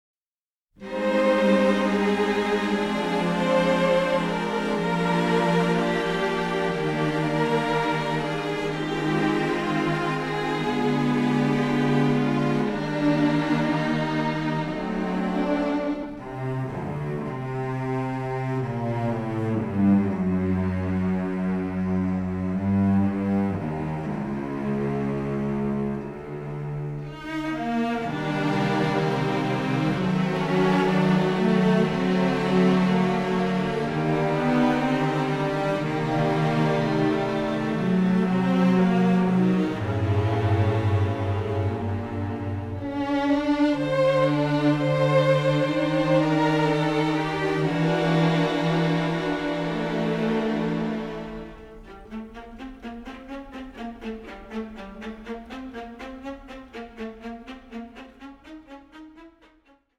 radiant melodramatic score